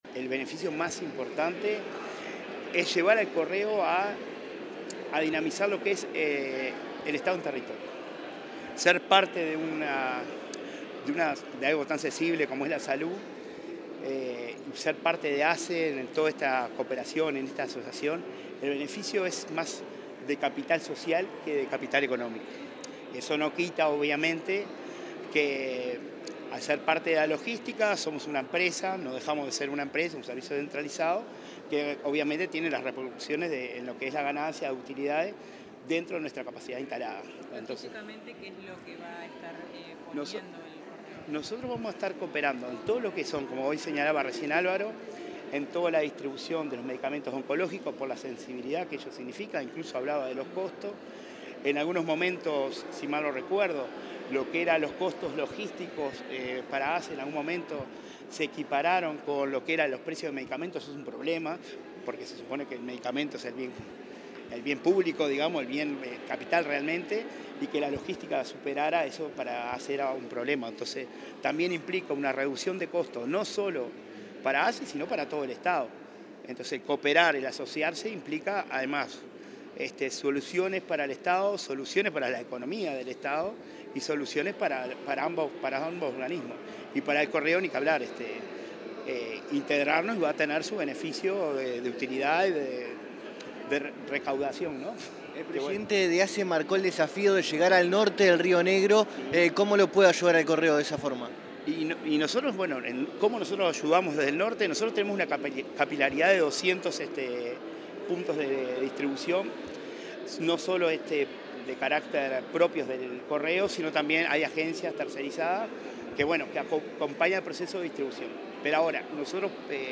Declaraciones del presidente del Correo Uruguayo, Gabriel Bonfrisco